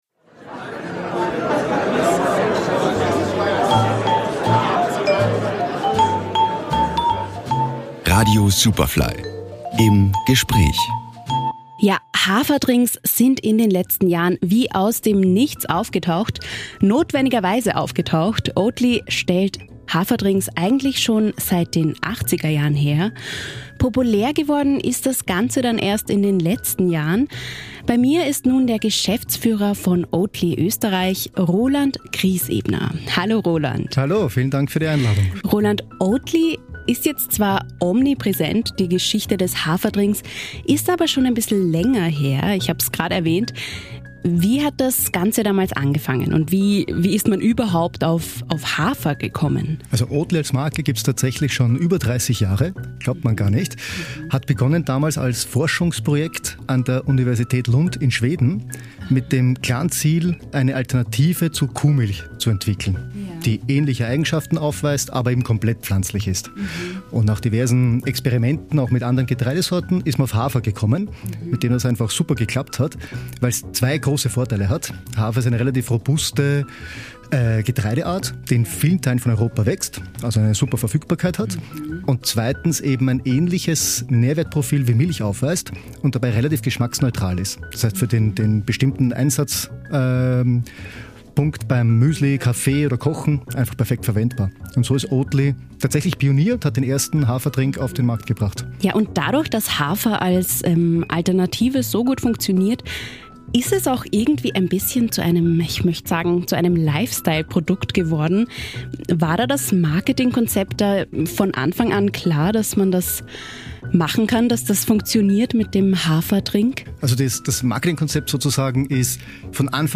Superfly Interviews